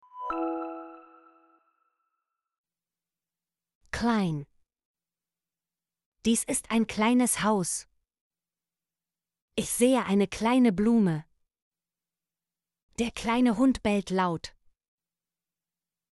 kleine - Example Sentences & Pronunciation, German Frequency List